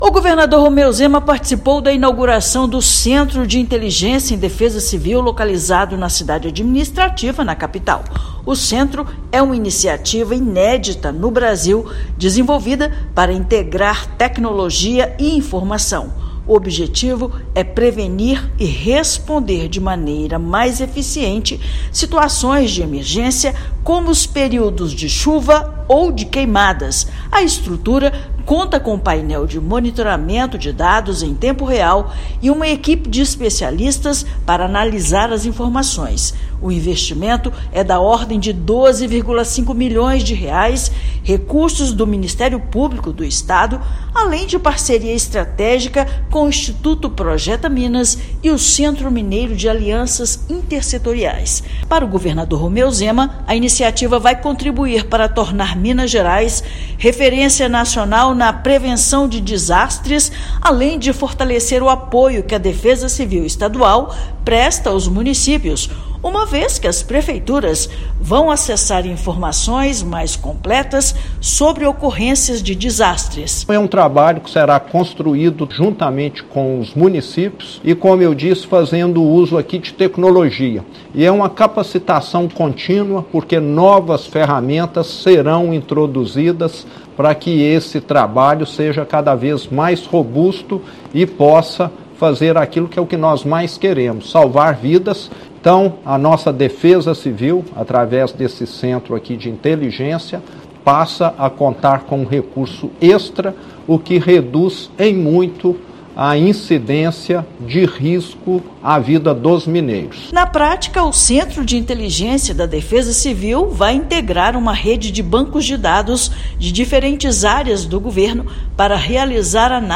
Novo complexo de informações instalado na Cidade Administrativa vai reunir banco de dados de diferentes órgãos do Estado, com o objetivo de transformar Minas Gerais em referência nacional na prevenção de desastres. Ouça matéria de rádio.